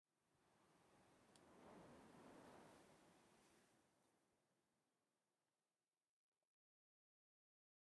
Minecraft Version Minecraft Version snapshot Latest Release | Latest Snapshot snapshot / assets / minecraft / sounds / block / dry_grass / wind2.ogg Compare With Compare With Latest Release | Latest Snapshot
wind2.ogg